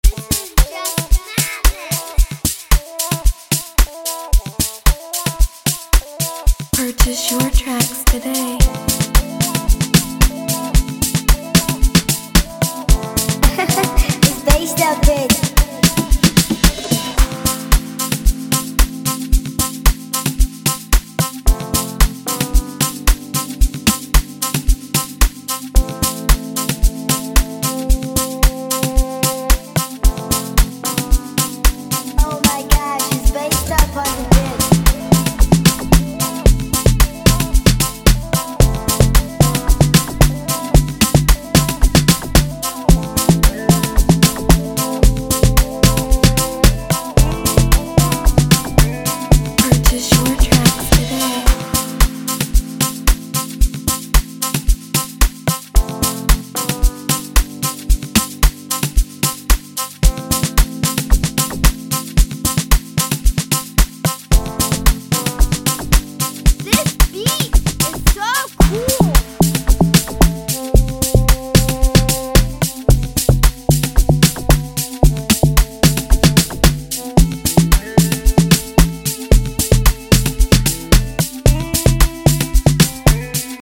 beat